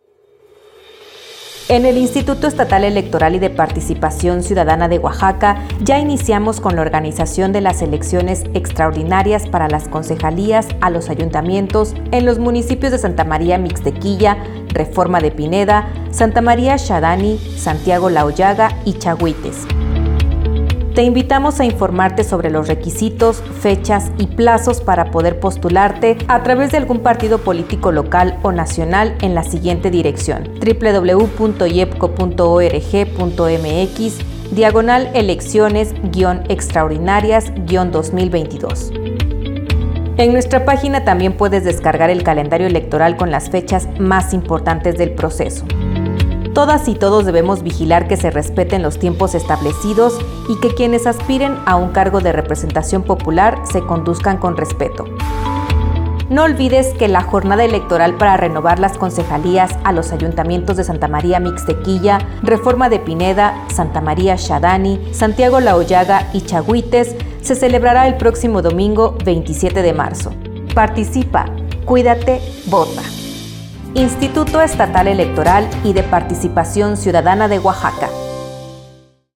Spot Concejalías